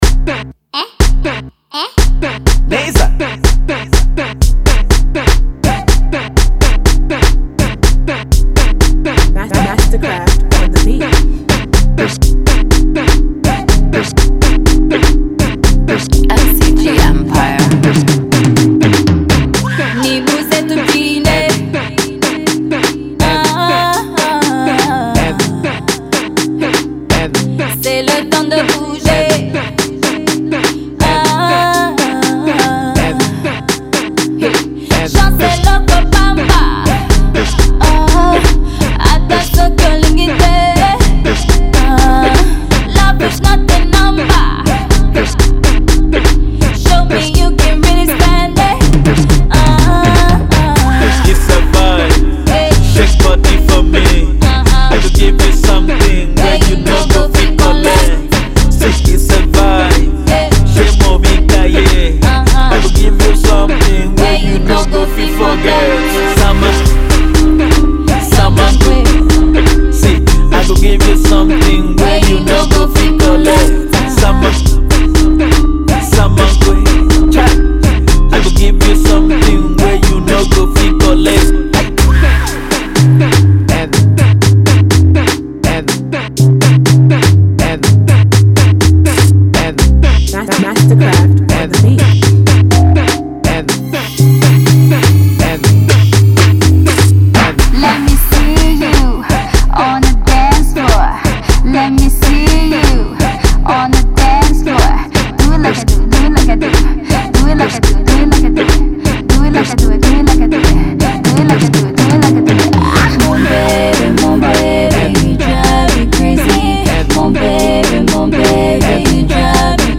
Lagos-based Rwandan singer